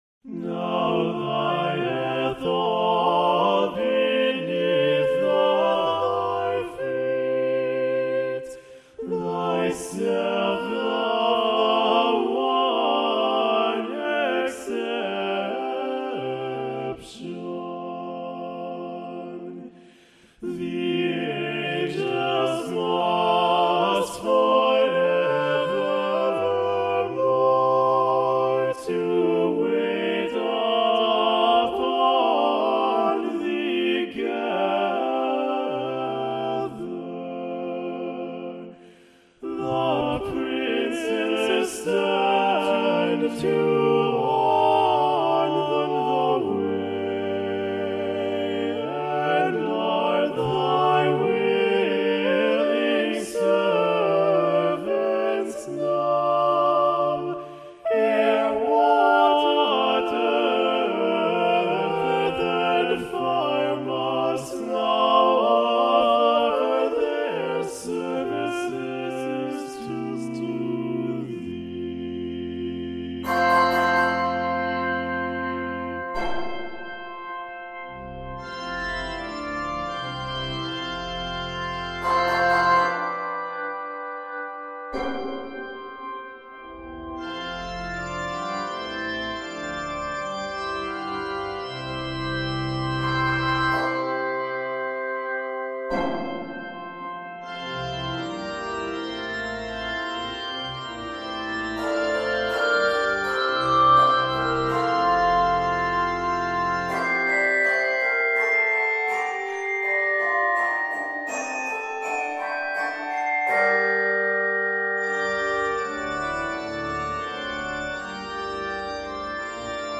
arranged for organ and 5 octaves handbells.
This work is 78 measures and is set in F major and G major.
Octaves: 5